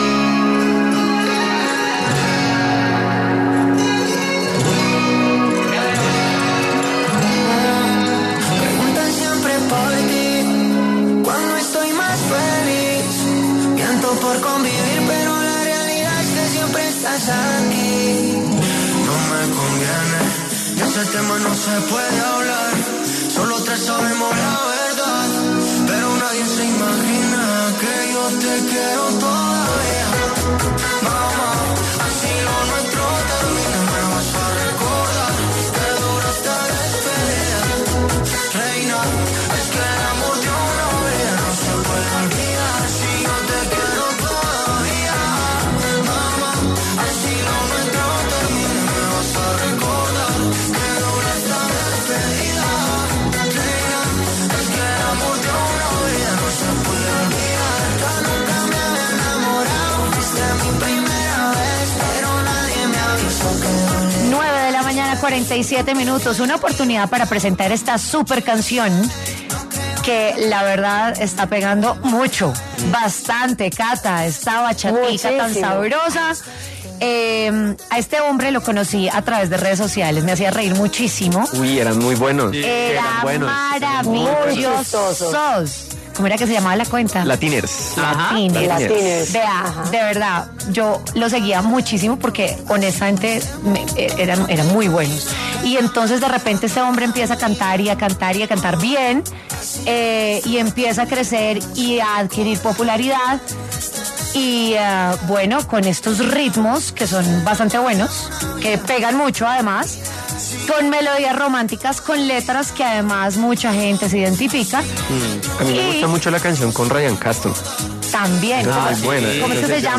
El cantante de pop Juan Duque conversó con W Fin De Semana acerca de su más reciente canción junto a Andy Rivera llamada ‘Ni con él, ni conmigo’